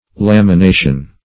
Lamination \Lam`i*na"tion\, n.